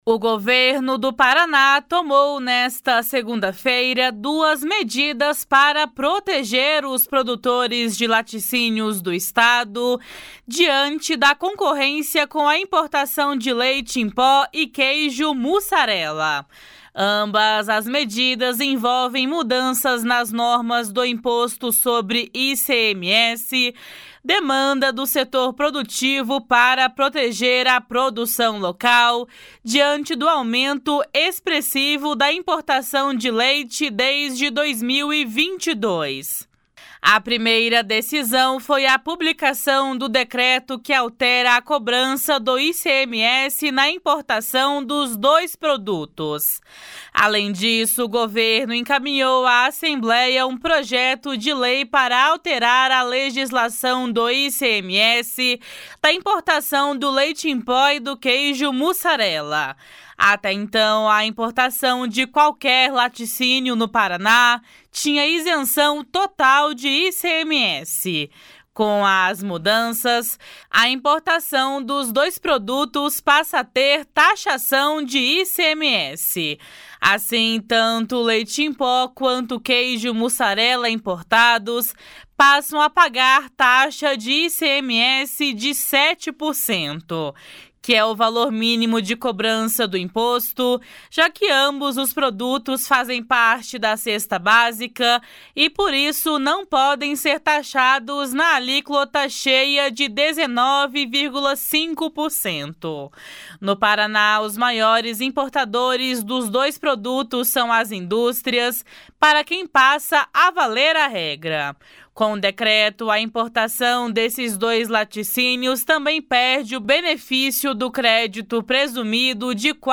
// SONORA NORBERTO ORTIGARA //